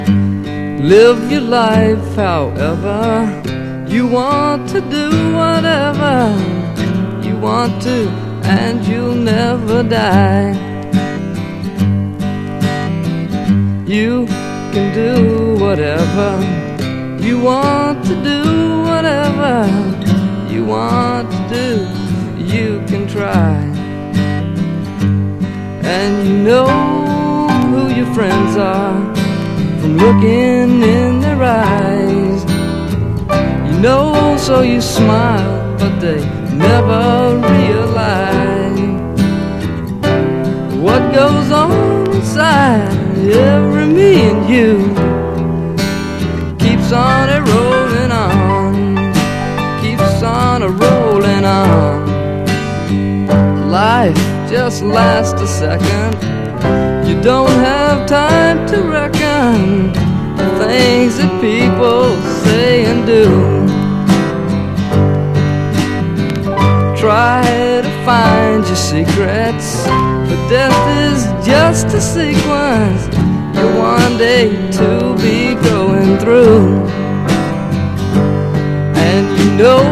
超強力手拍子リズム＆ブルース/ロック
本格R&Bなサウンドはもちろん、キュートなコーラスも入ったりするのが本作の凄いところ。